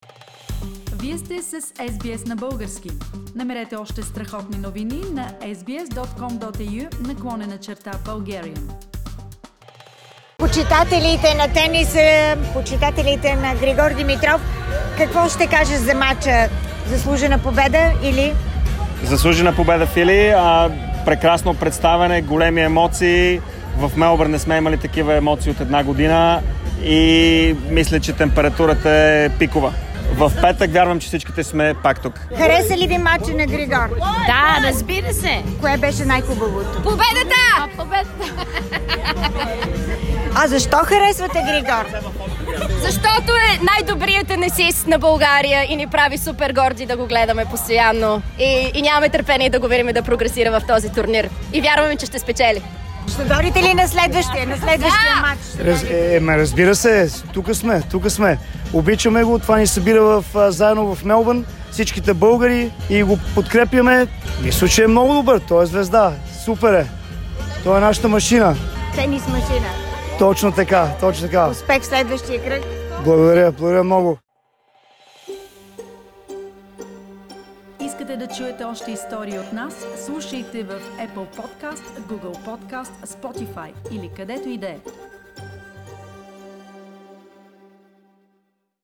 Loud cheers by Bulgarian fans for Grigor Dimitrov
Grigor Dimitrov continued in the third round of the Australian Open after a convincing victory against the hosts' representative Alex Bolt - 7: 6, 6: 1, 6: 2. Here are some reactions by Grigor's fans after the match that ended at midnight. No voice was spared throughout the 3 setter.